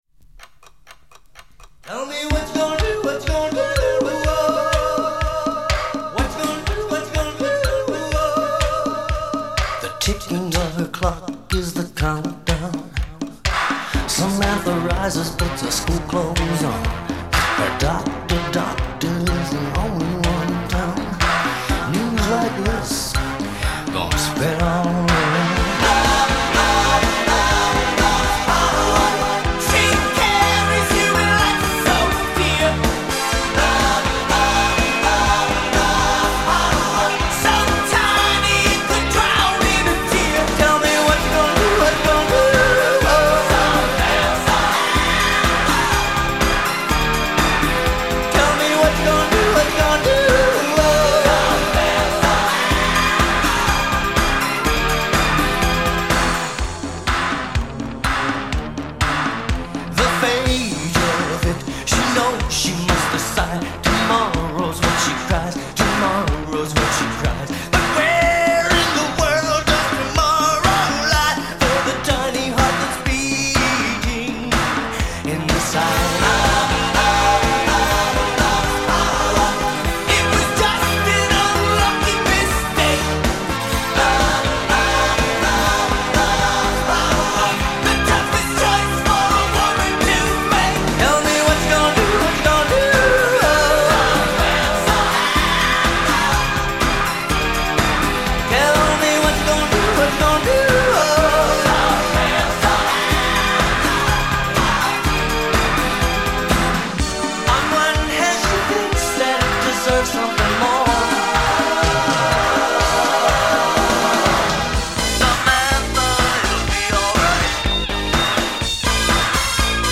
pop song